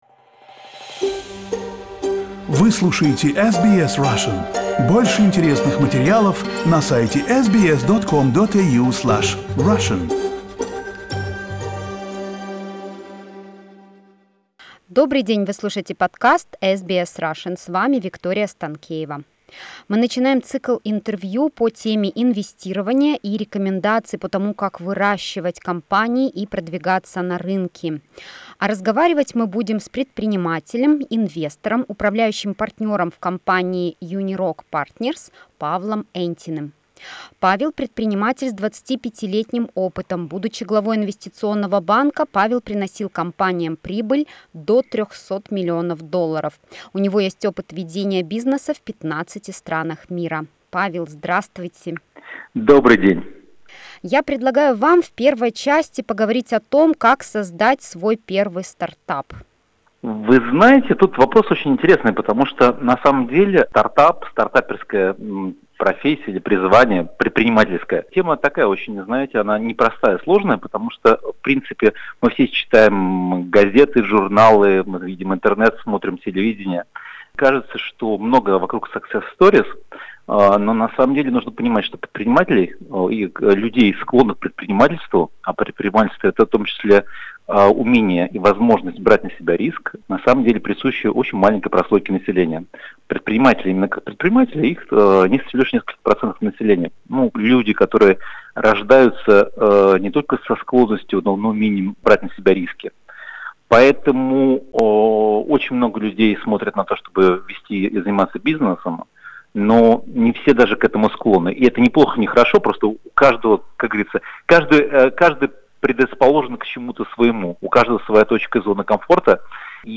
Мы начинаем цикл интервью с советами по поводу того, как создавать, выращивать компании и продвигать их на рынки.